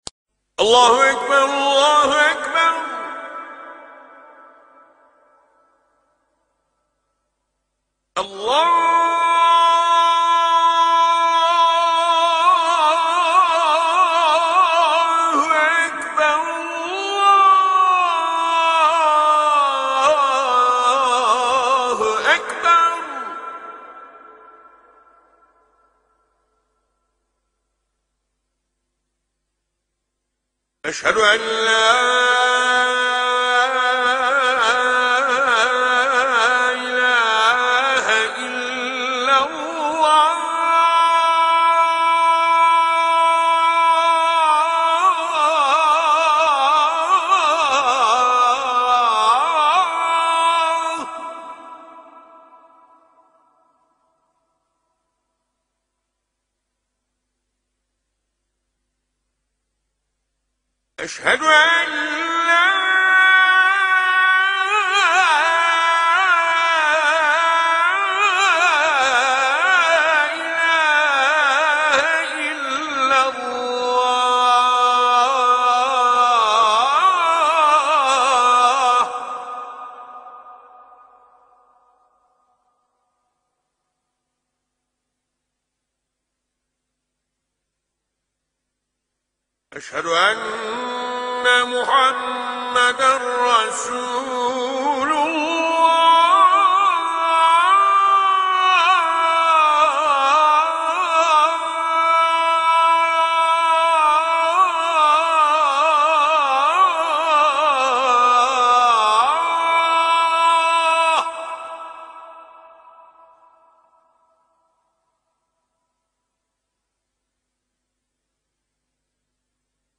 الأذان بصوت مؤذن من تركيا